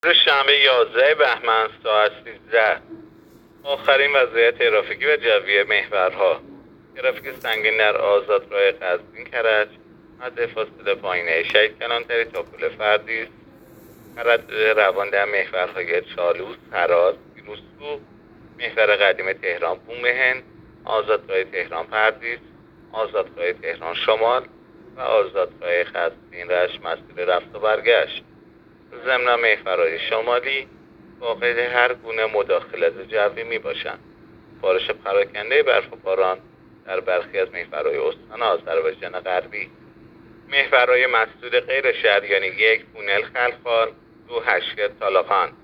گزارش رادیو اینترنتی از آخرین وضعیت ترافیکی جاده‌ها ساعت ۱۳ یازدهم بهمن؛